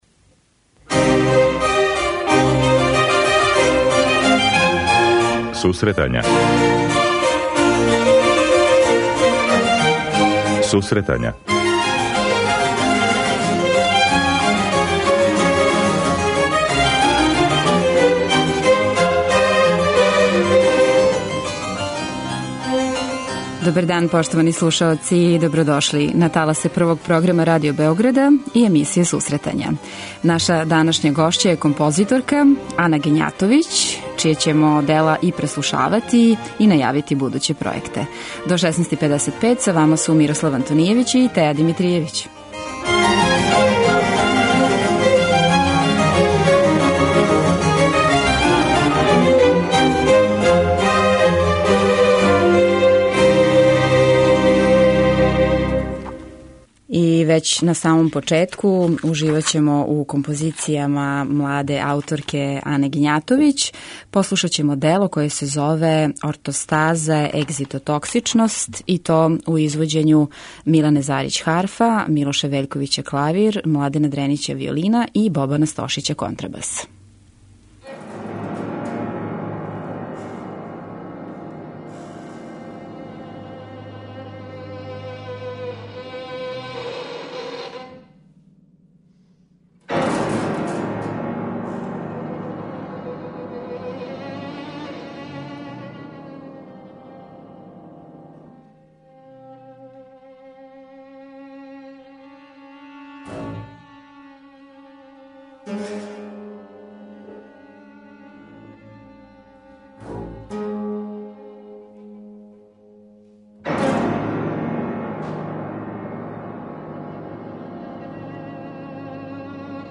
преузми : 19.66 MB Сусретања Autor: Музичка редакција Емисија за оне који воле уметничку музику.